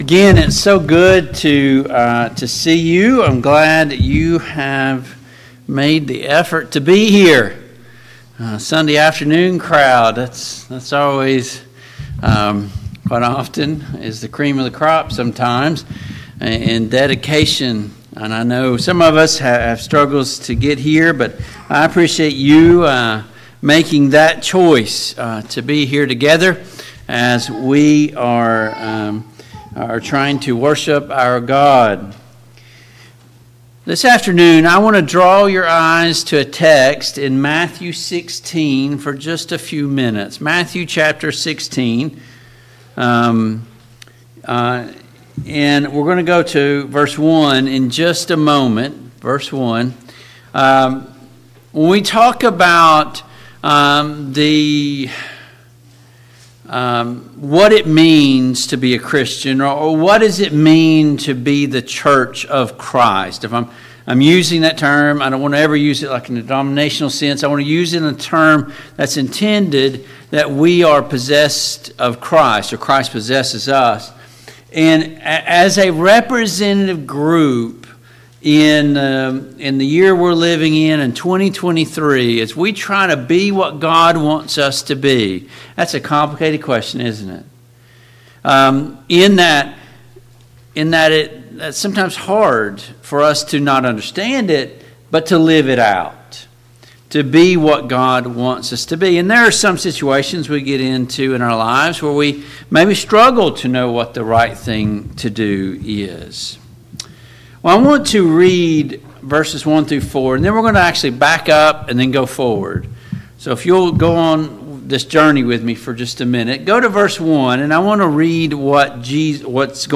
Matthew 15:32-39 Service Type: PM Worship Topics